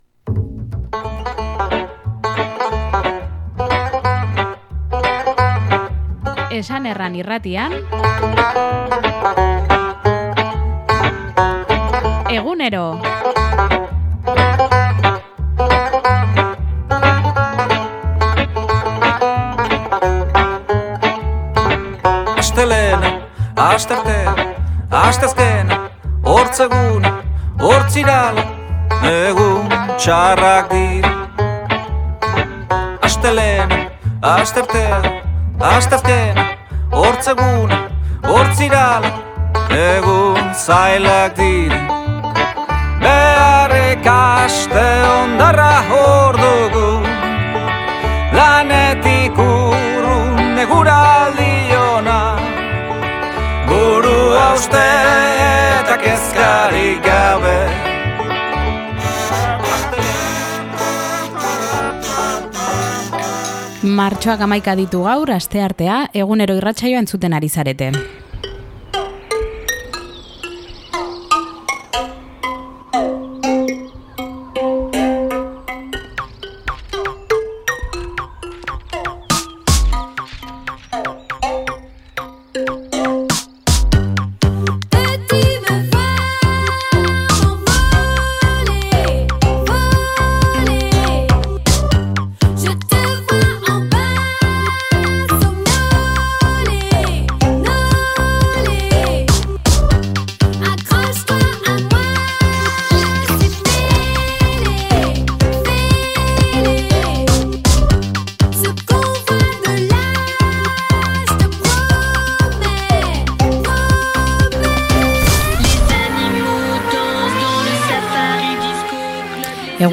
eskualdeko magazina